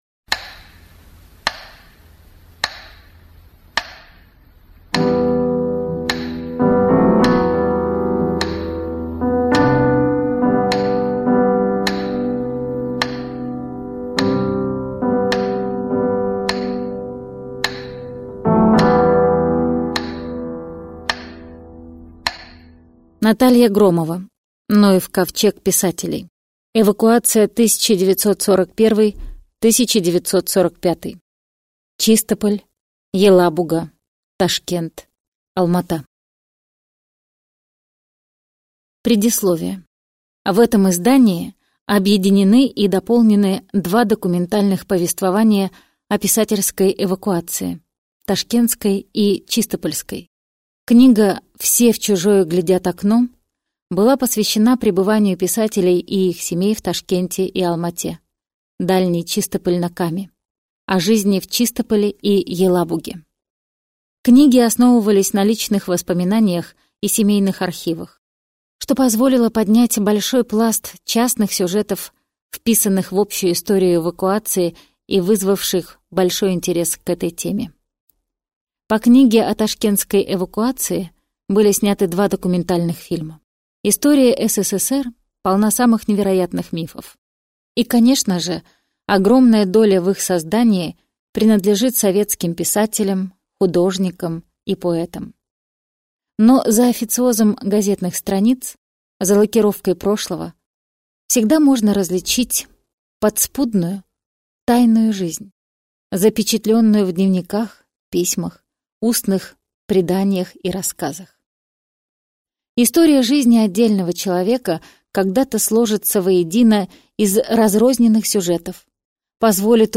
Аудиокнига Ноев ковчег писателей. Эвакуация 1941–1945. Чистополь. Елабуга. Ташкент. Алма-Ата | Библиотека аудиокниг